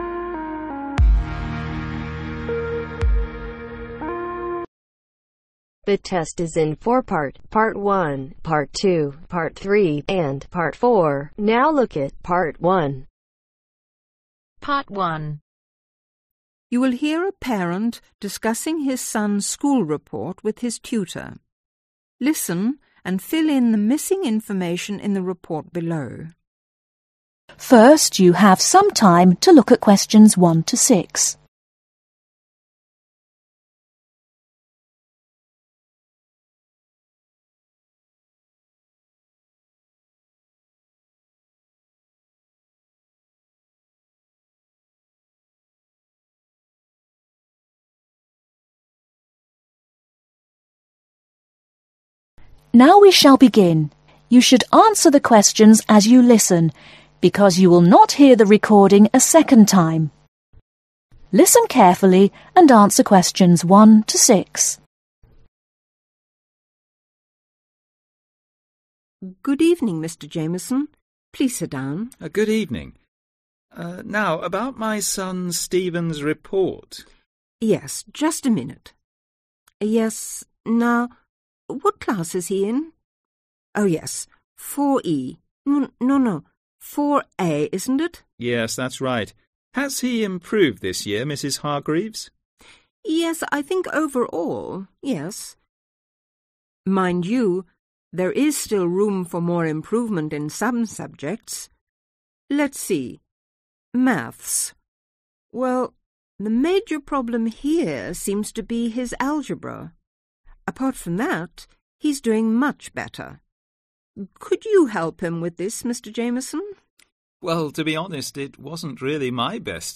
Academic ielts listening test with answers